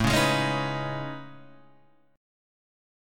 A Major Sharp 11th